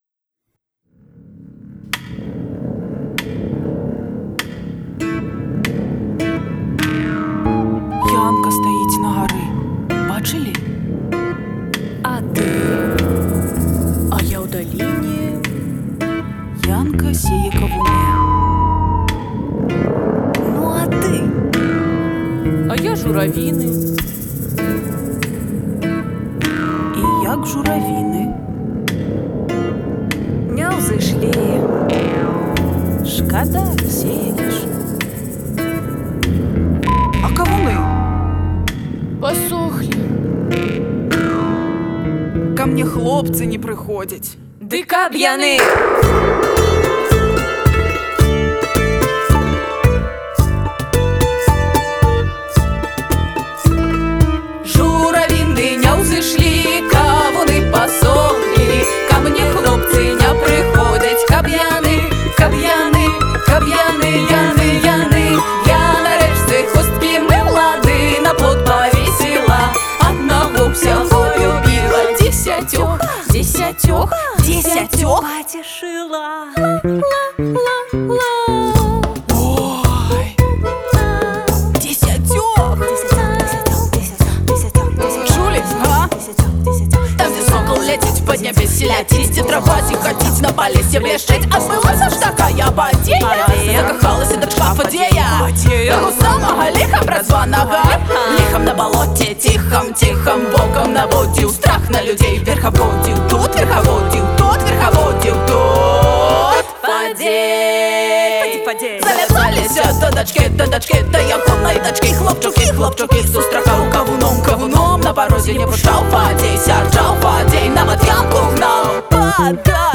Дзяўчаты з фольк-гурту
запісаны ў межах студыйнай сэсіі